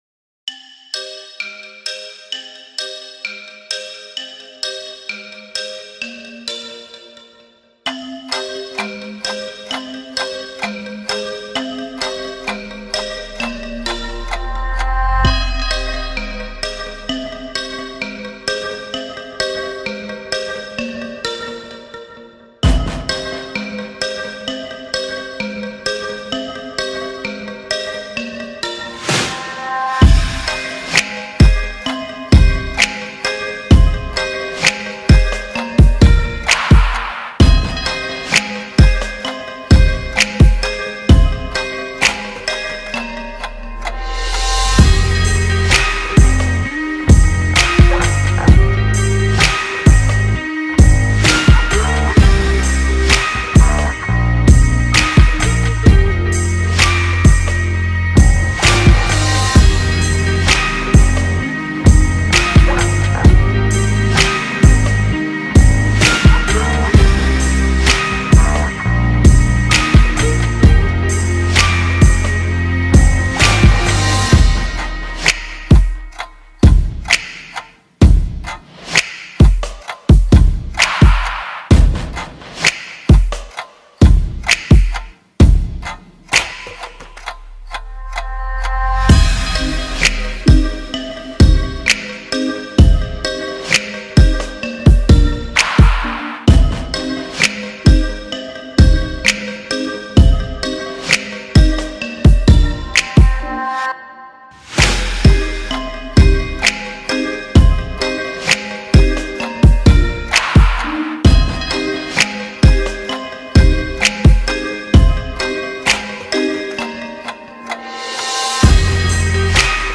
Instrumentais